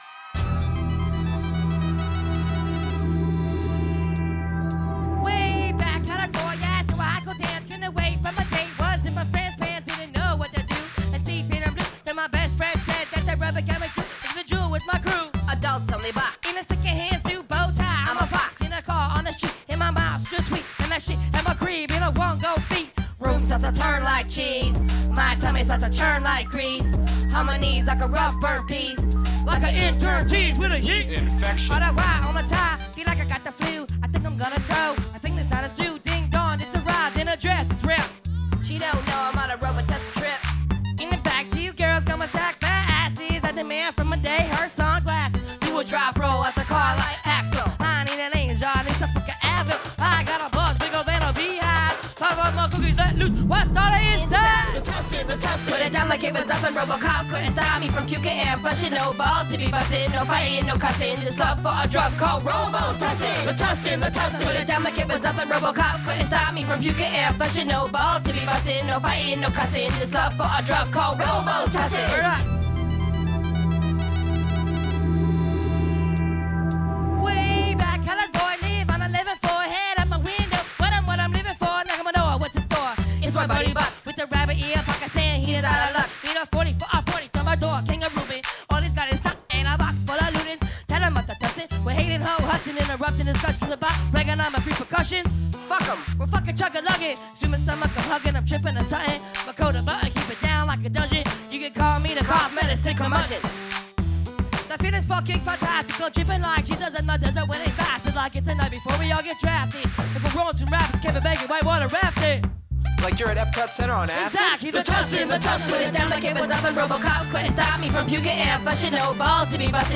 austin, tx at emo's on november 21st 2005